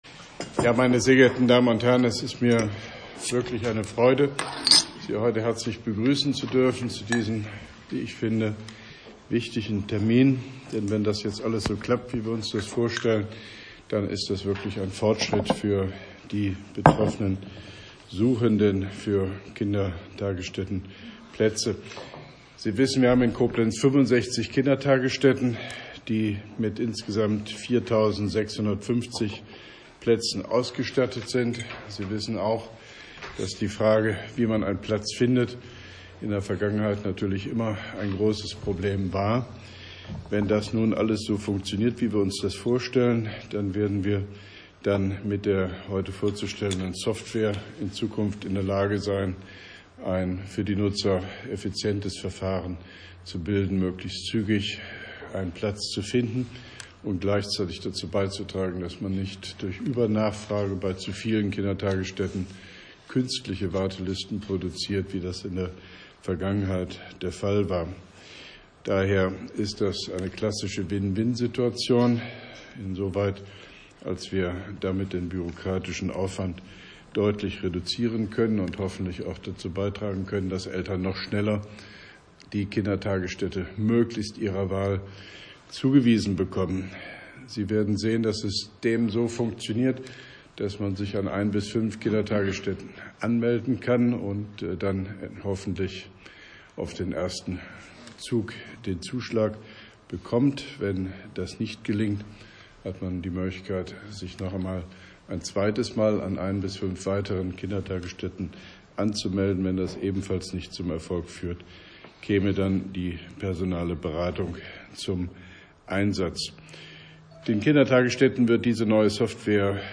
Grußwort von OB Hofmann-Göttig bei der Pressekonferenz anlässlich der Live-Schaltung des “Kita-Elternportals” “Little Bird” der Stadt Koblenz, Koblenz 18.01.2018